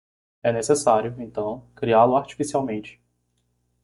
Pronounced as (IPA)
/aʁ.t͡ʃi.fi.siˌawˈmẽ.t͡ʃi/